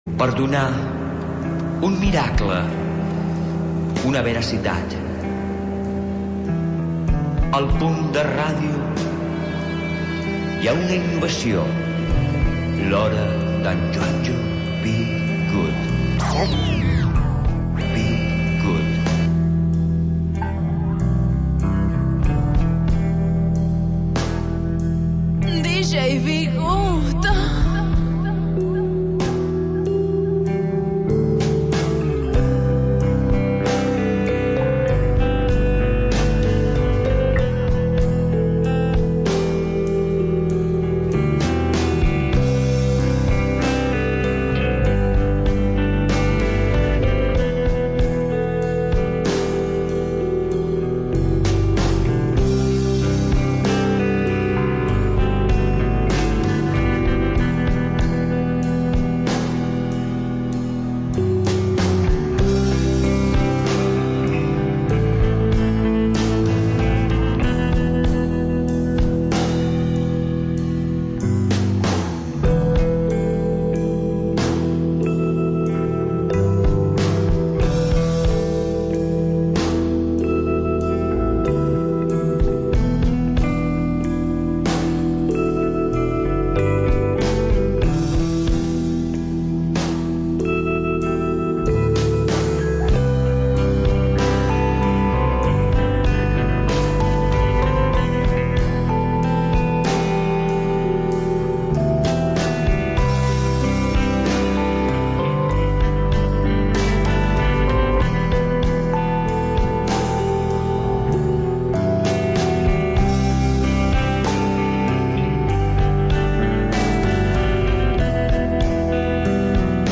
Selecció musical independent